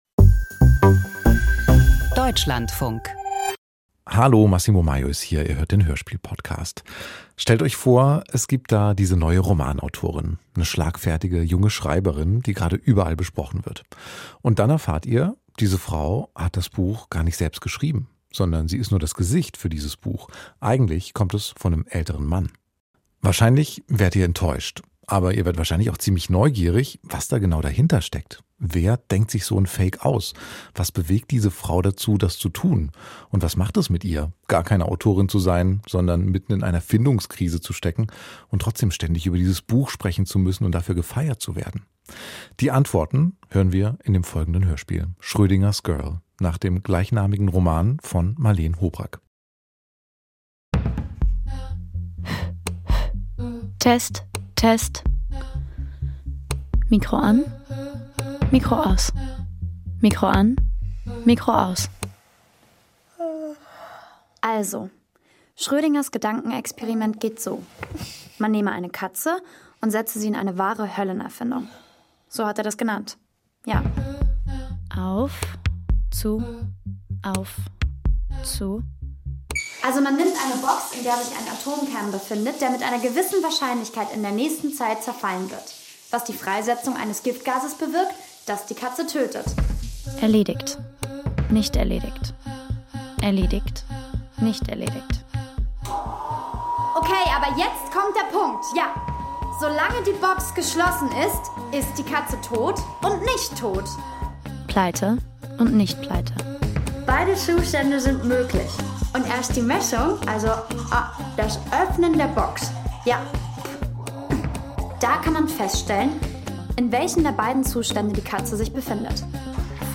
• Satire • Mara Wolf wird in einen Coup verwickelt. Der Literaturbetrieb sucht Authentizität.